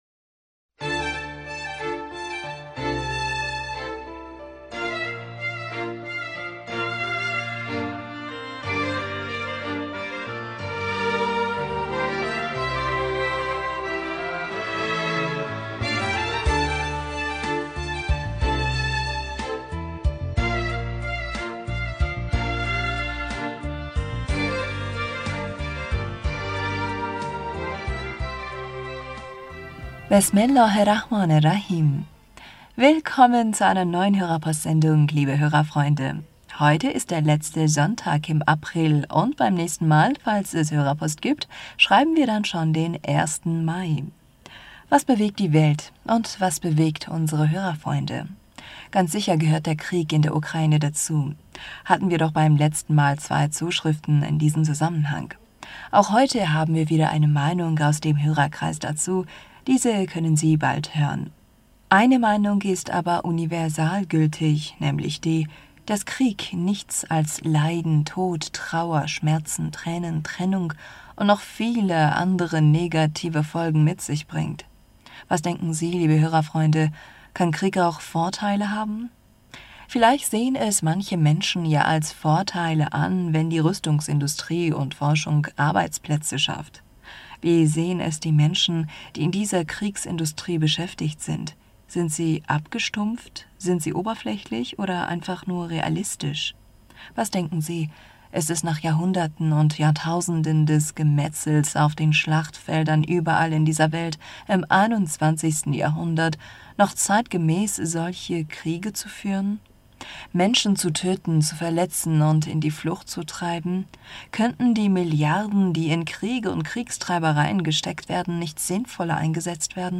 Hörerpostsendung am 24.April 2022